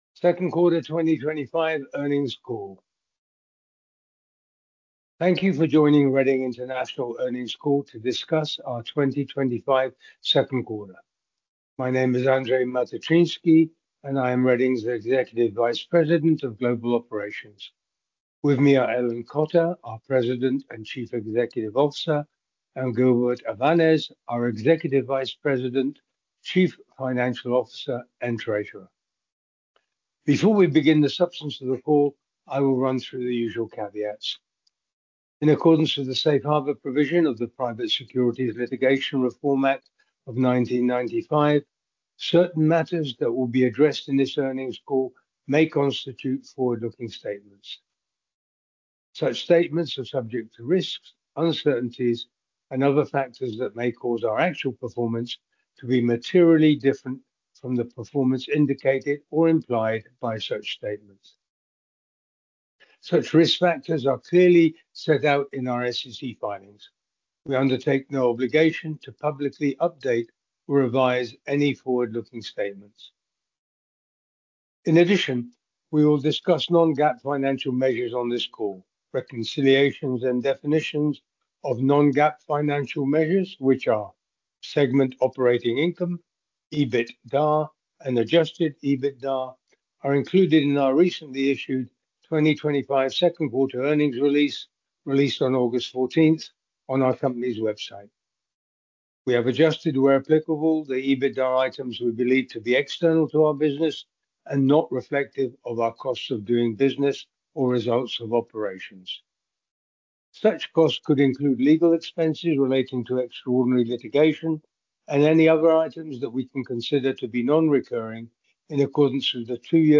Question and answer session will follow the formal remarks.